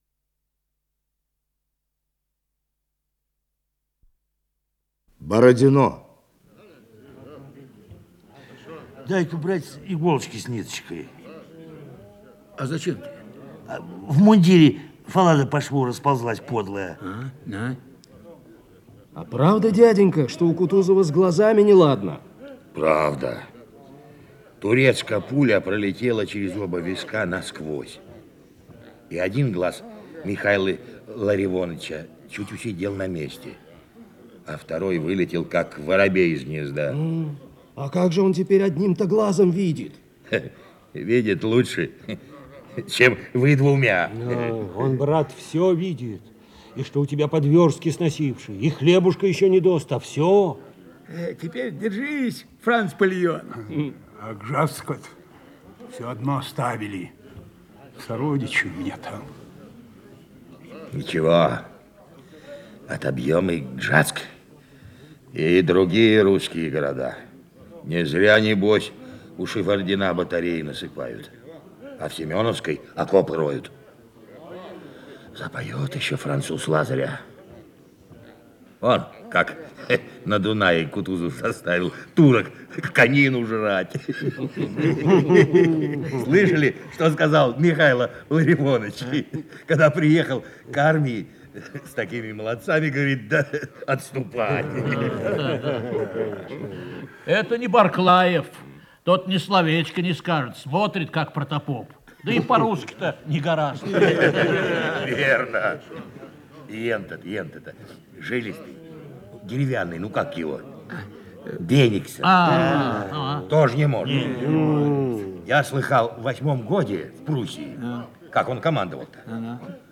Исполнитель: Артисты Ленинградских театров
Радиоспектакль